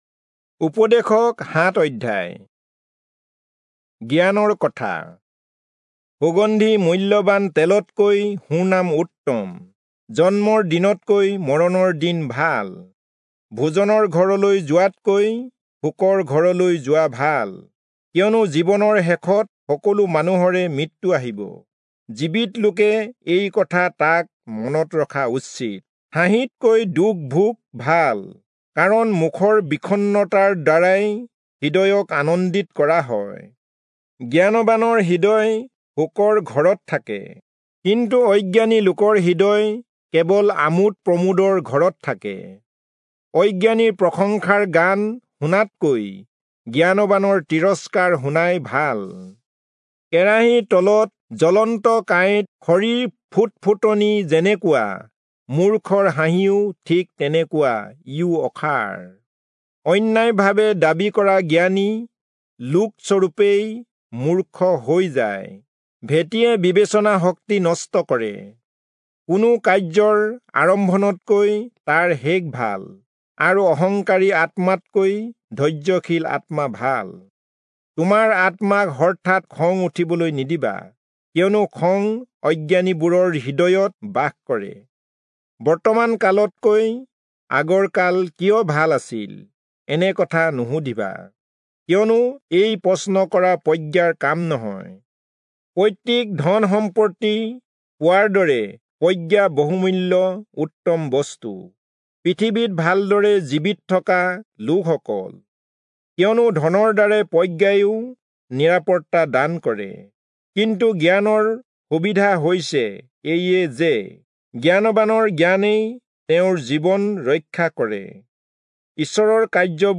Assamese Audio Bible - Ecclesiastes 1 in Irvml bible version